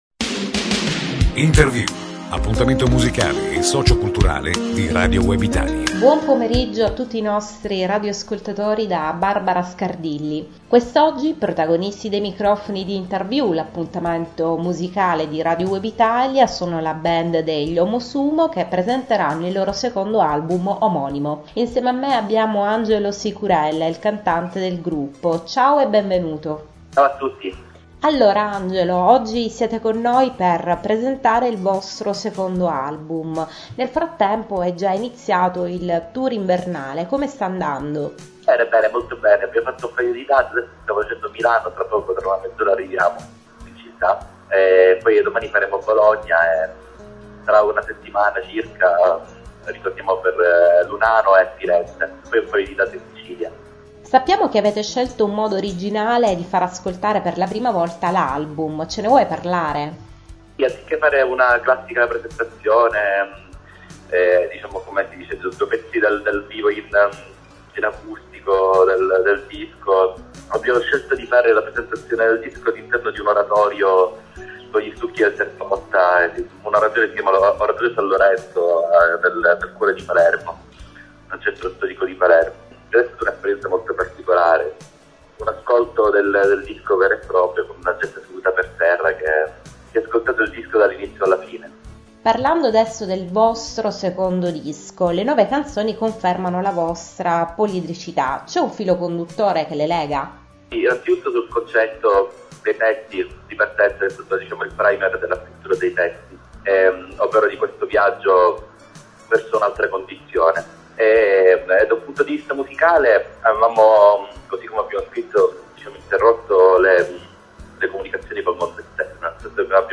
Omosumo: l’intervista per l’uscita del nuovo disco omonimo - Radio Web Italia